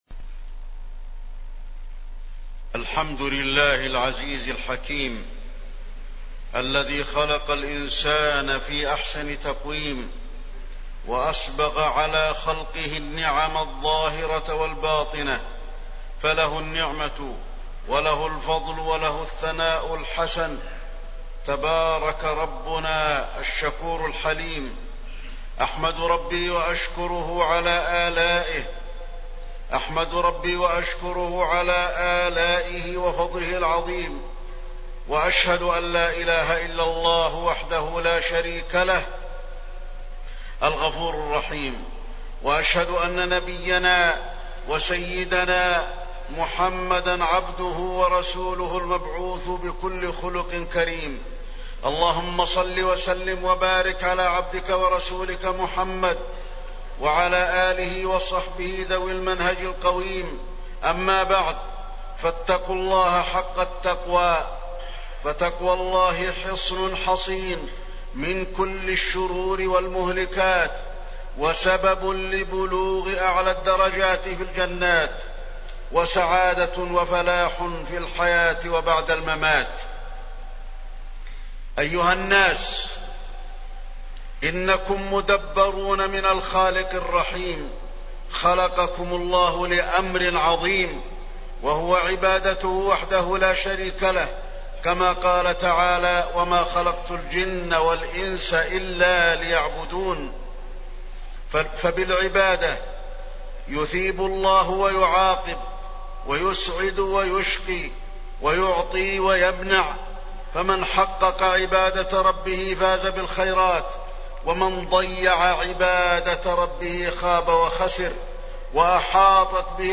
تاريخ النشر ٦ جمادى الأولى ١٤٣٠ هـ المكان: المسجد النبوي الشيخ: فضيلة الشيخ د. علي بن عبدالرحمن الحذيفي فضيلة الشيخ د. علي بن عبدالرحمن الحذيفي منازل الآخرة